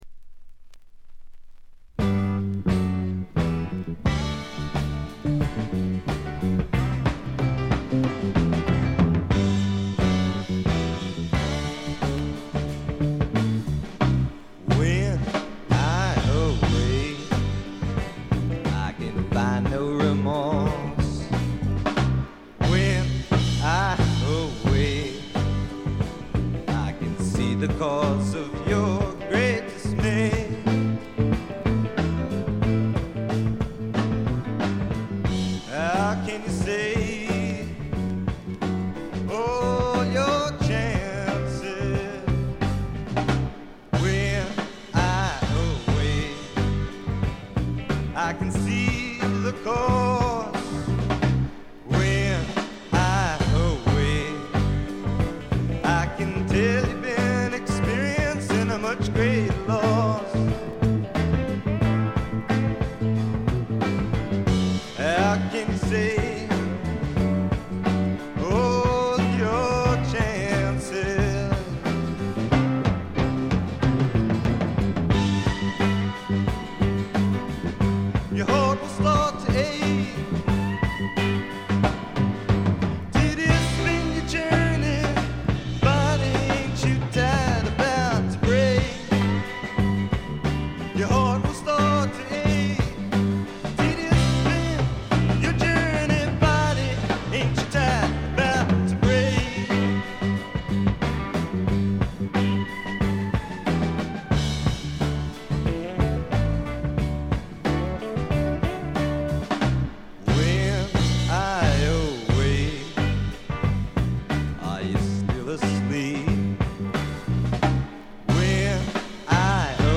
チリプチ少々、散発的なプツ音少し。
スワンプ／シンガーソングライター・ファンなら必携のスワンプ名作です。
試聴曲は現品からの取り込み音源です。